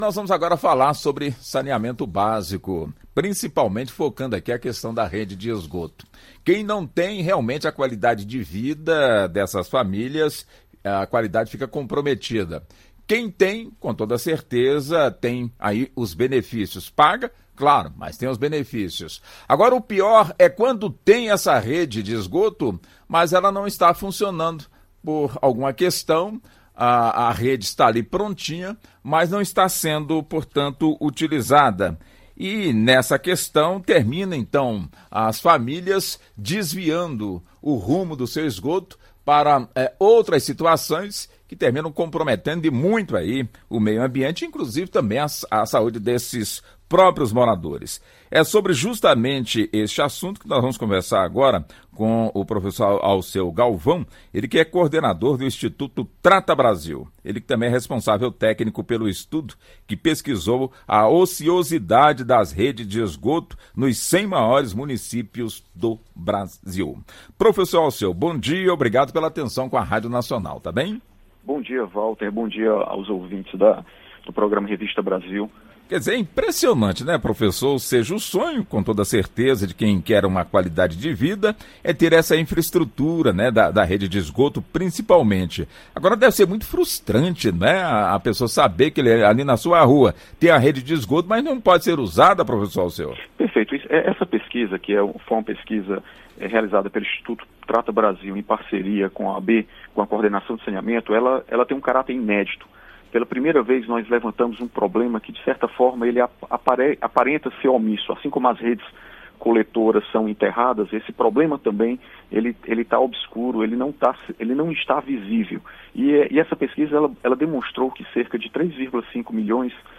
Confira a entrevista na íntegra no player acima! O Revista Brasil é uma produção das Rádios EBC .